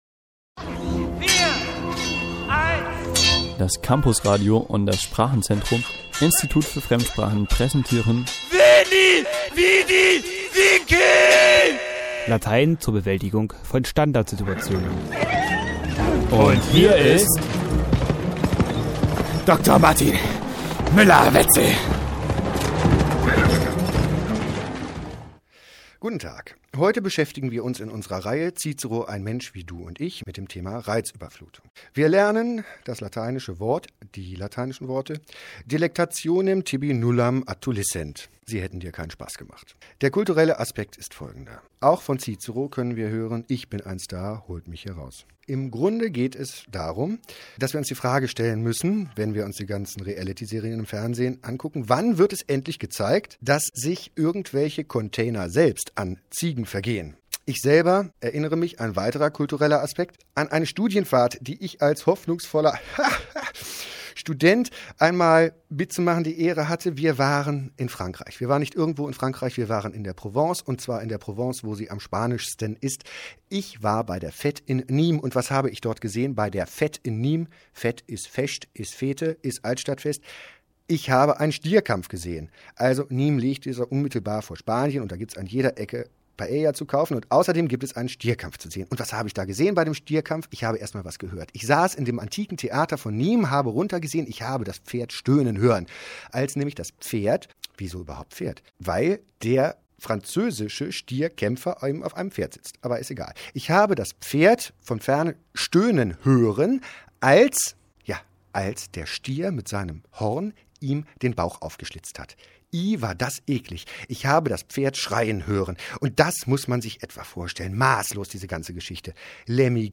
Radiolatein-Klassiker aus dem Campusradio als MP3
Achten Sie jeweils auf den Anspann: herrlich, wie das scheppert!